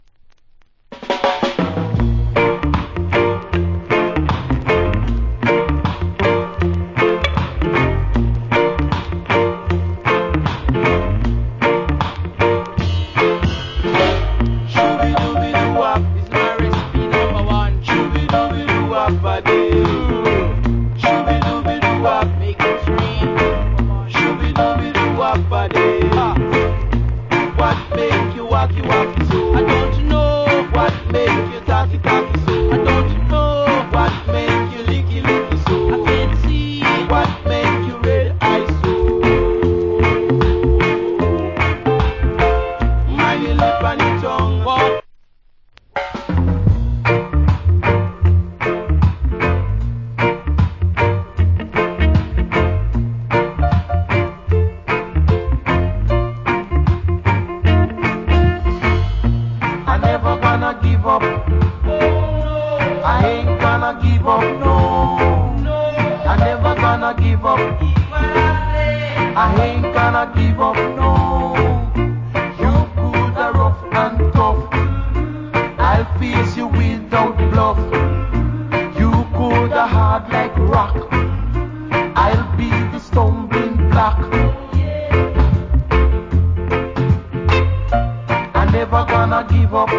Wicked Rock Steady Vocal.